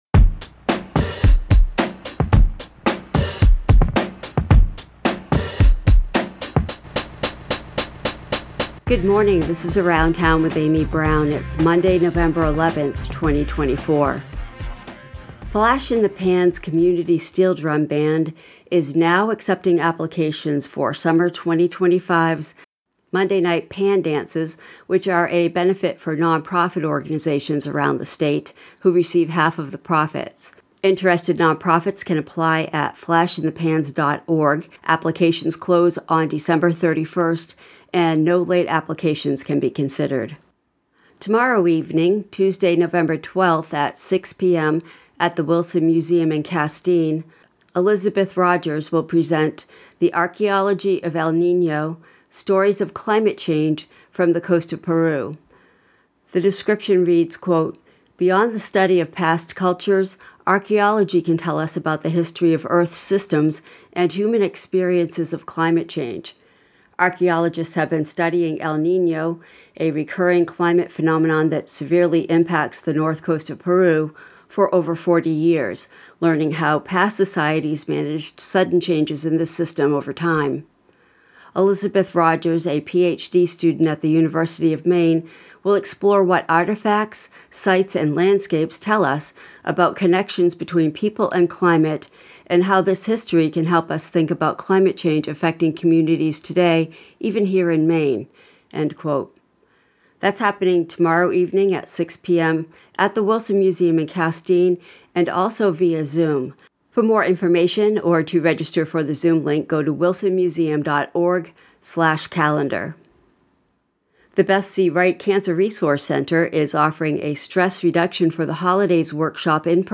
Local news & events